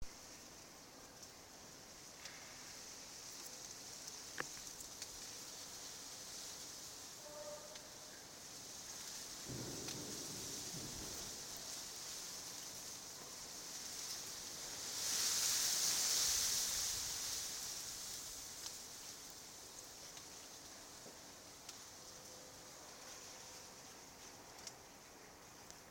The wind rustling the bamboo in the Robin Beatson Garden, Merton College